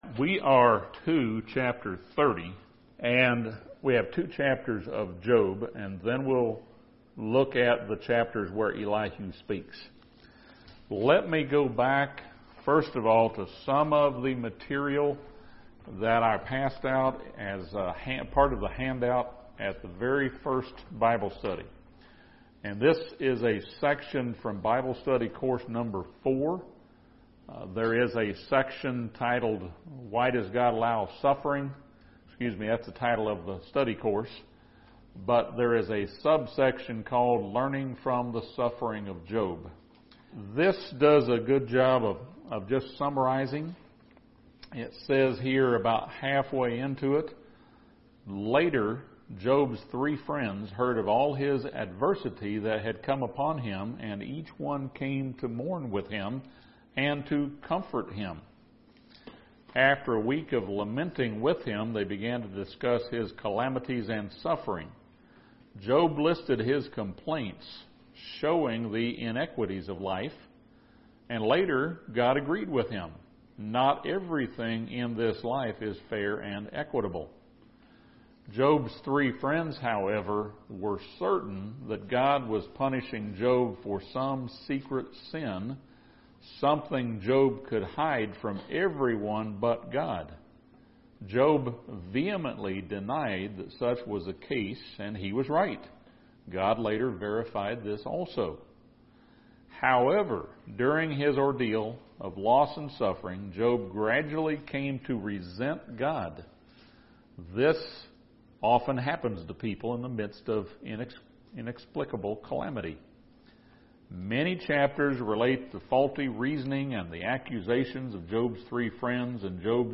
This Bible Study surveys chapters 30-37 of Job.